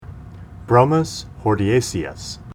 Pronunciation Cal Photos images Google images
Bromus_hordeaceus.mp3